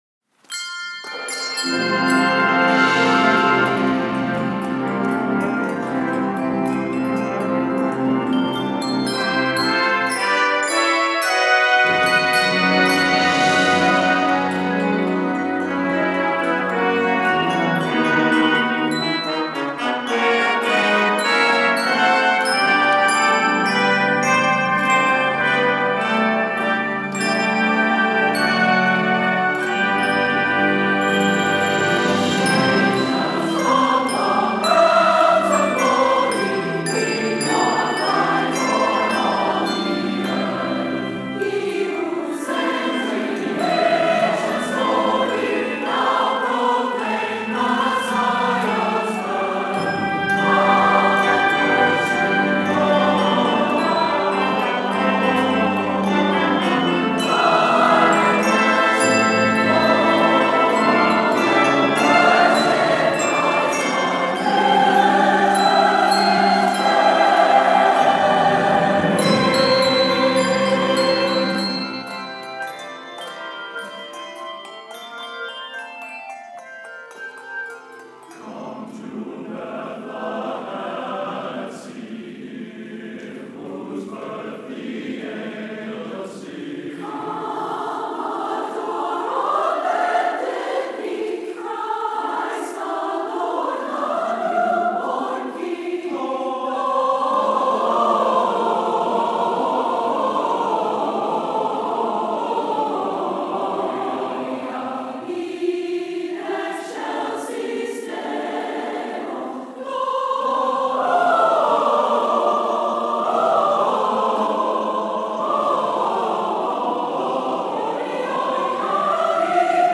stirring arrangement
handbell piece